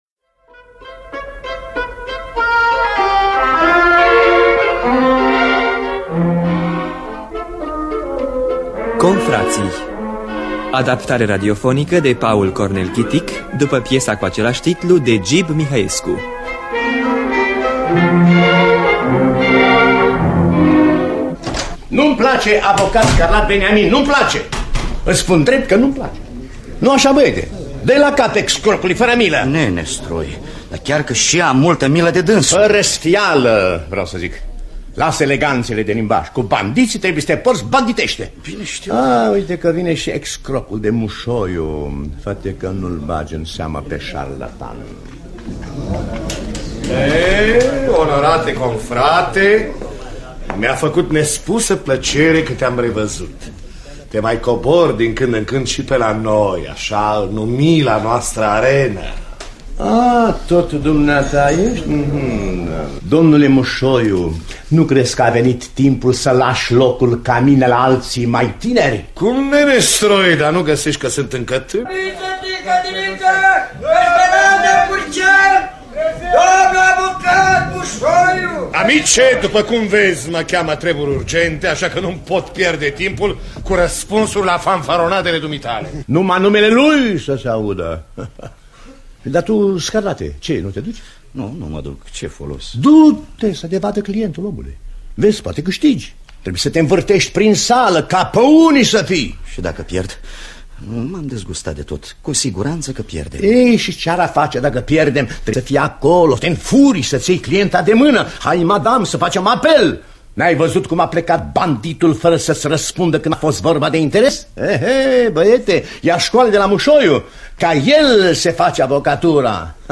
Confrații de Gib Mihăescu – Teatru Radiofonic Online